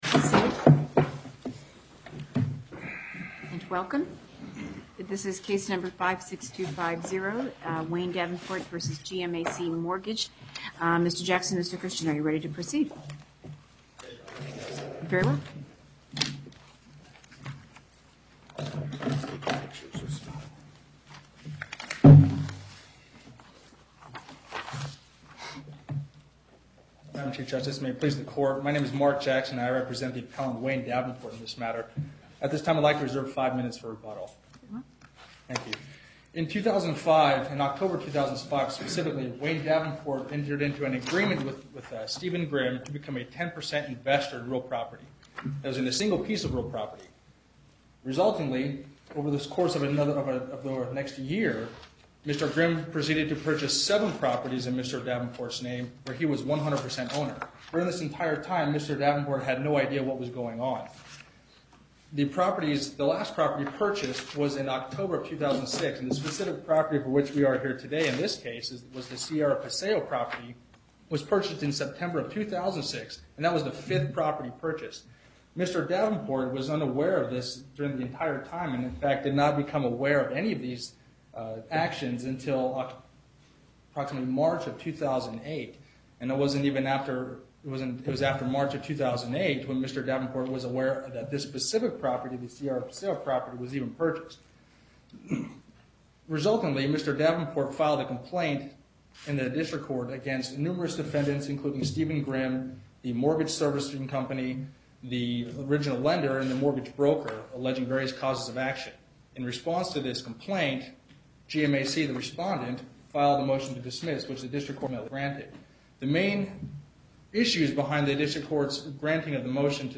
Location: Las Vegas En Banc Court, Chief Justice Siatta Presiding
as counsel for the Appellant
as counsel for the Respondent